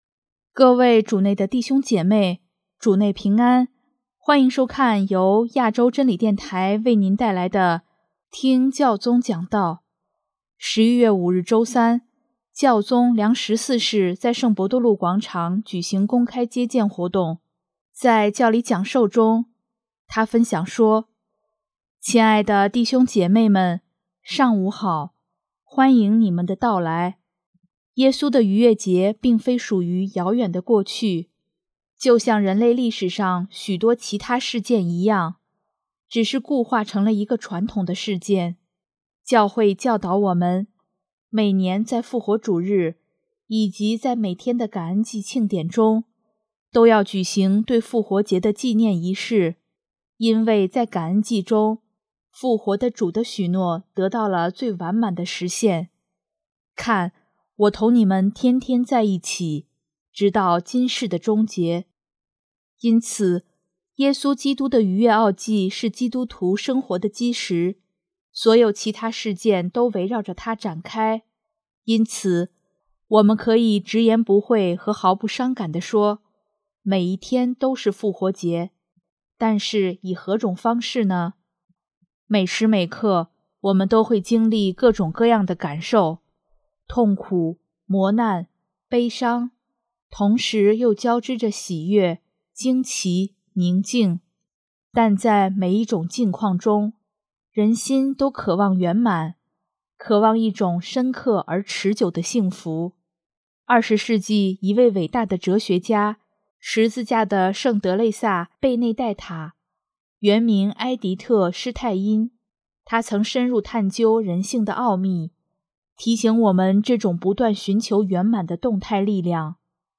【听教宗讲道】|每一天都是复活节
11月5日周三，教宗良十四世在圣伯多禄广场举行公开接见活动。